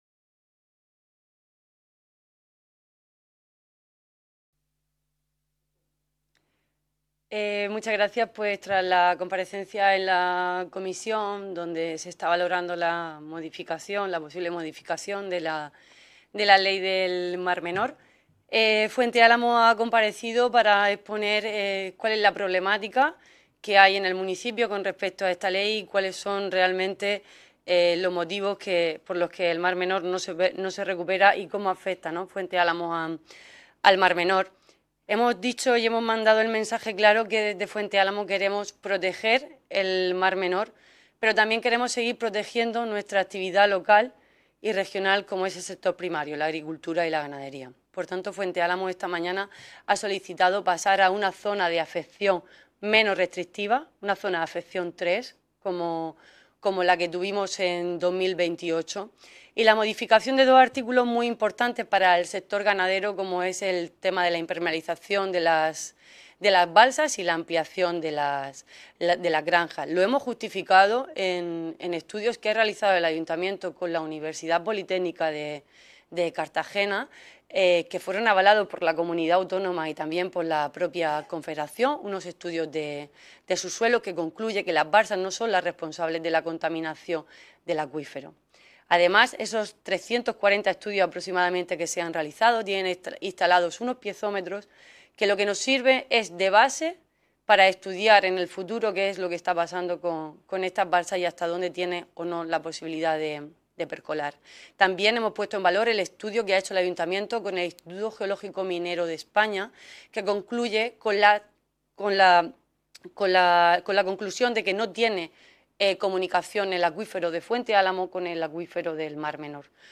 Ruedas de prensa tras la Comisión de Asuntos Generales e Institucionales, de la Unión Europea y Derechos Humanos
• Juana María Martínez García, alcaldesa del Ayuntamiento de Fuente Álamo